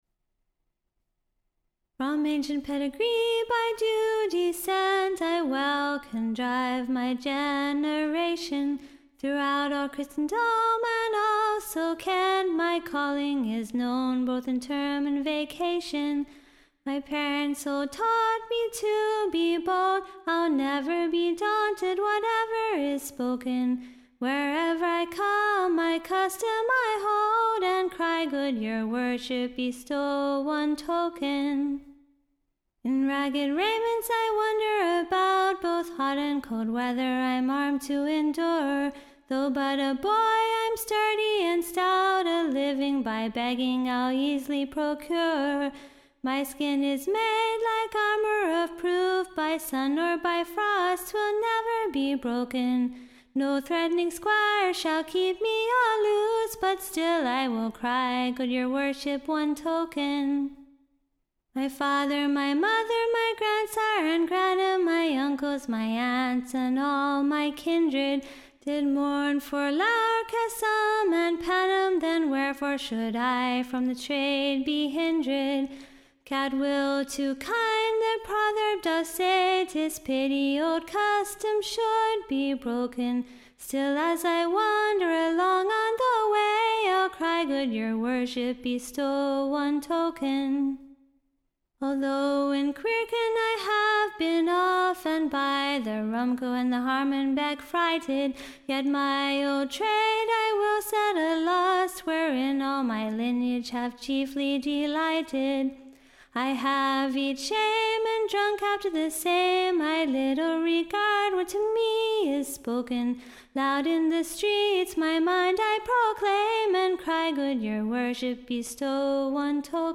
Ballad
Which is to be sung to a Tune so nam'd.